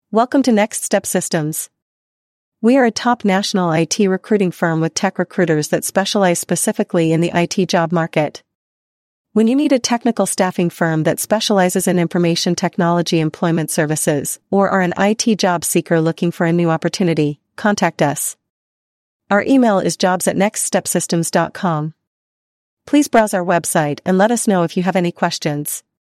Please take a moment to listen to an audio file about our IT staffing company services generated by Artificial Intelligence (AI).